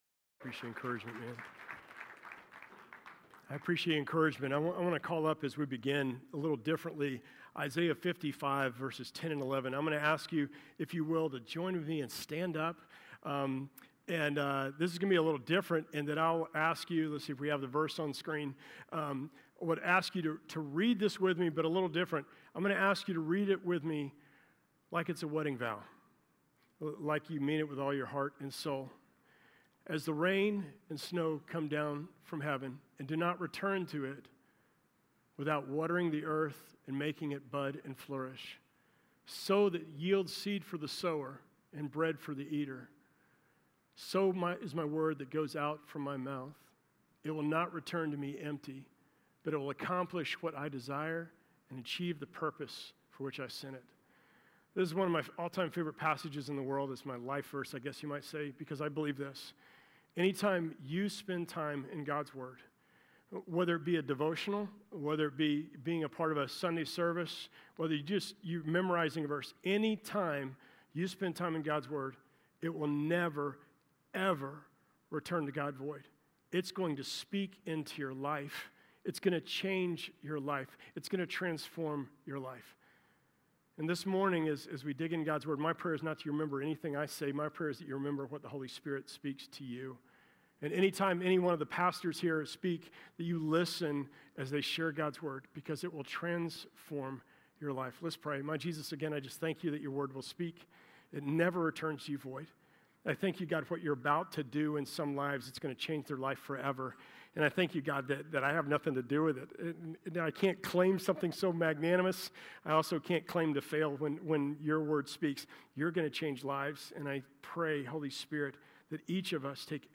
Sermons – Hope Church Augusta